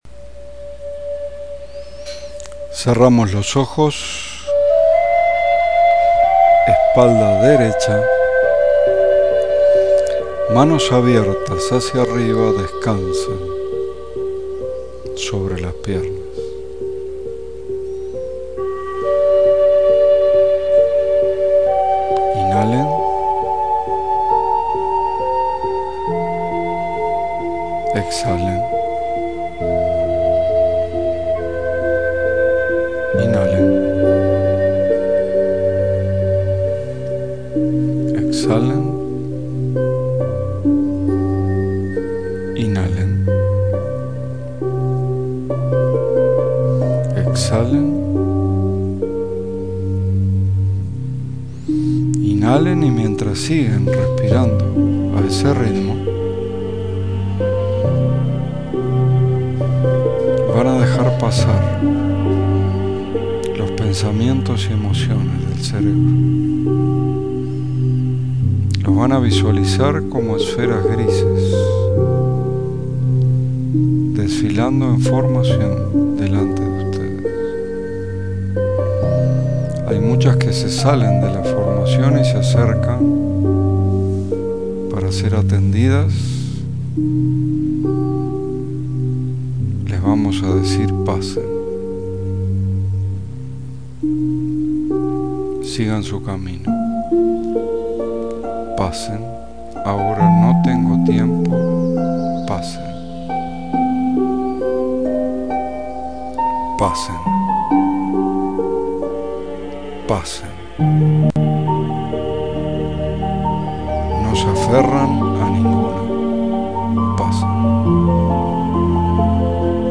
Meditación para escuchar al corazón
Meditacion-escuchar-al-corazon.mp3